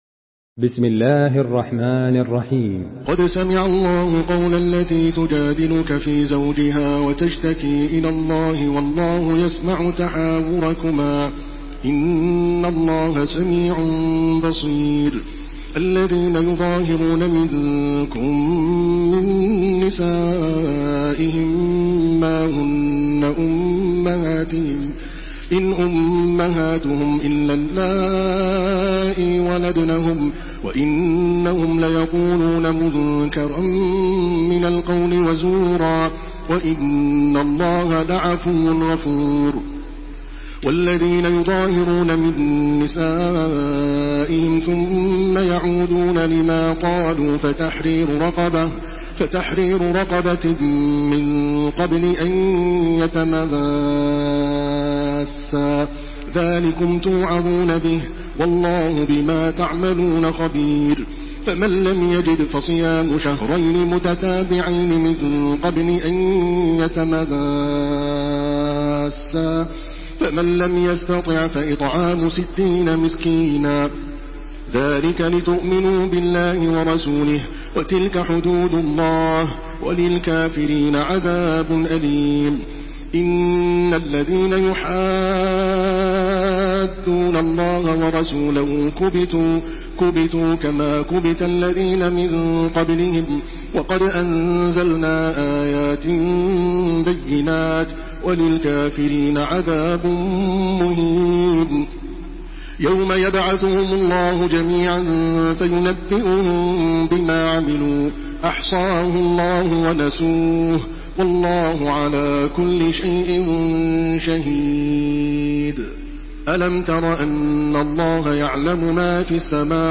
تحميل سورة المجادلة بصوت تراويح الحرم المكي 1429
مرتل حفص عن عاصم